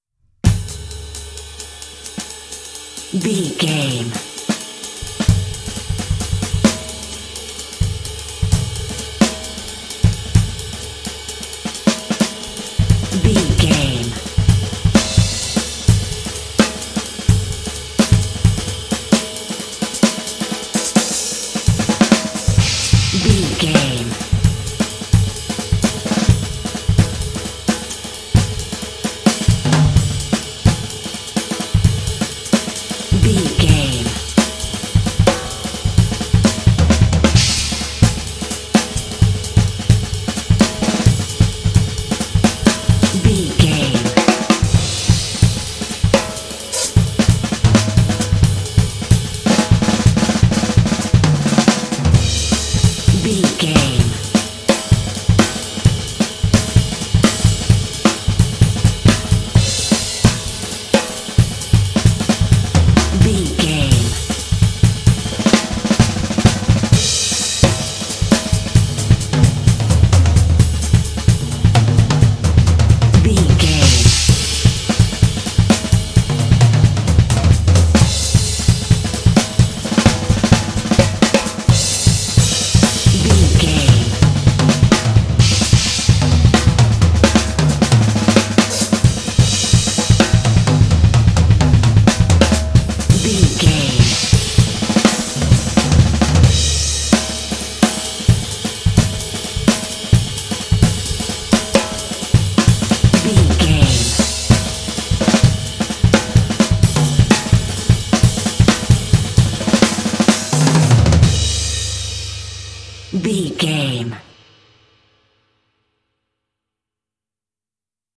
Atonal
groovy
jazz drums